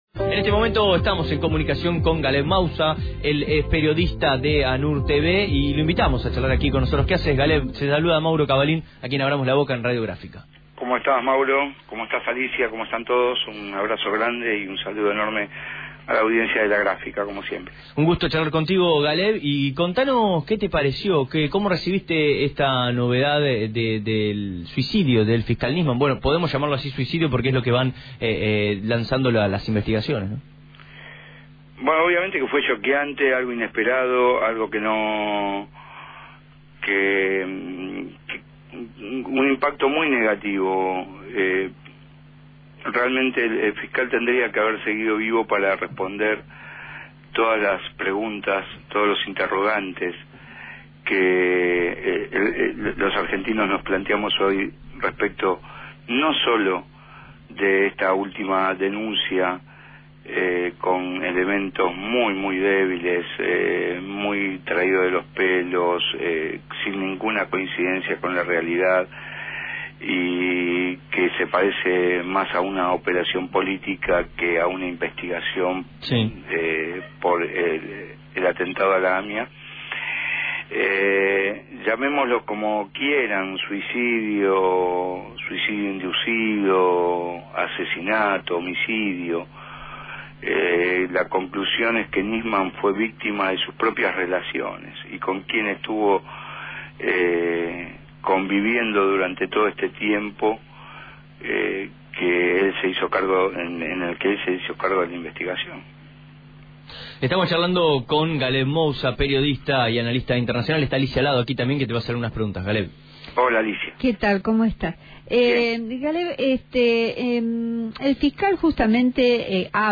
dialogó con Abramos la Boca para analizar y repasar el accionar del fiscal.